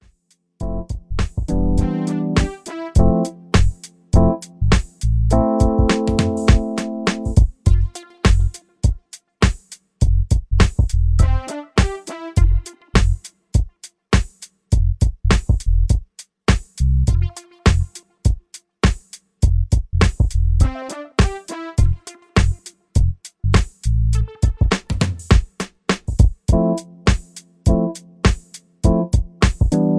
High Energy Club Track